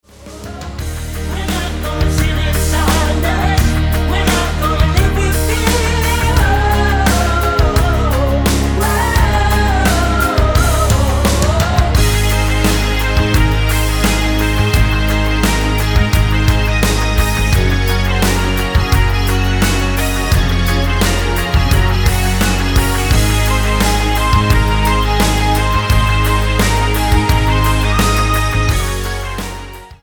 Tonart:Eb mit Chor